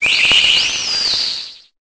Cri de Marshadow dans Pokémon Épée et Bouclier.